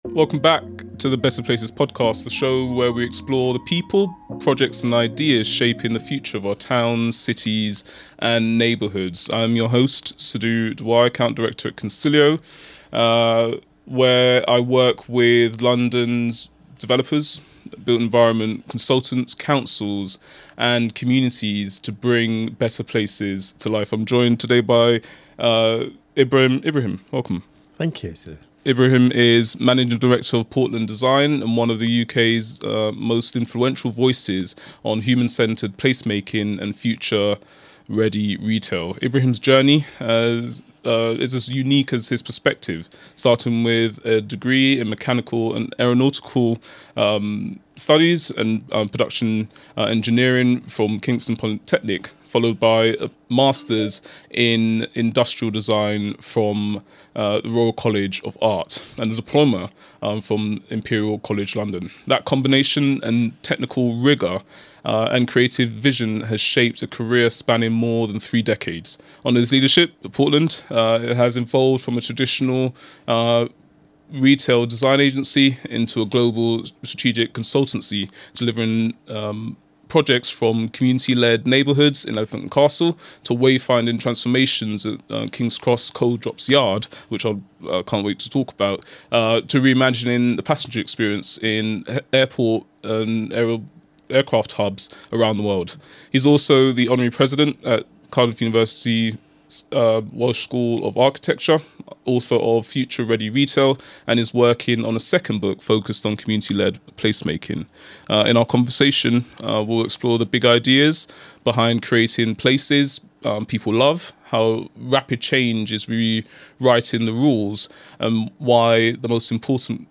The conversation highlights why future-ready places are measured not just in footfall, but in emotional resonance, and why collaboration between designers, developers, and communities is critical to creating thriving, meaningful destinations.